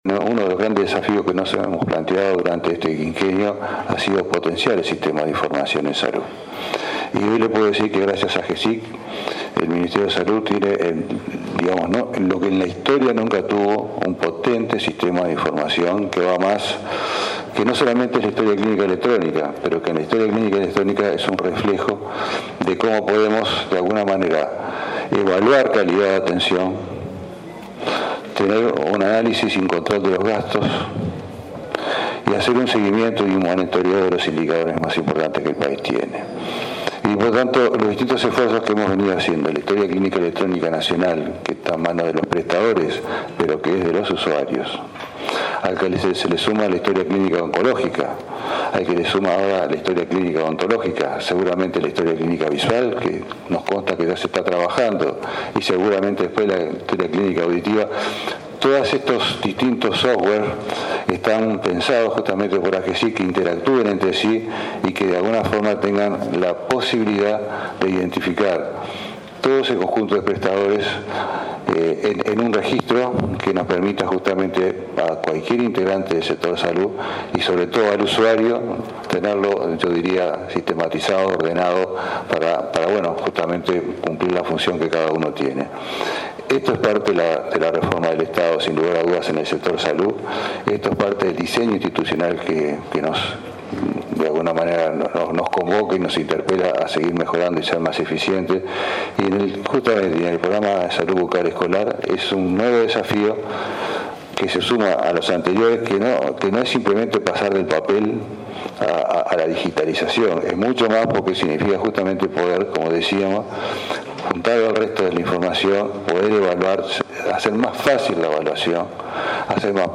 El ministro de Salud, Jorge Basso, sostuvo que la historia clínica electrónica permitirá a futuro hacer un seguimiento de los indicadores del país y que interactúen la historia clínica odontológica con la visual y la auditiva, proporcionando información a prestadores y usuarios. “Esto es parte de la reforma del Estado en el sector salud”, dijo en el lanzamiento de la historia clínica electrónica del Programa de Salud Bucal.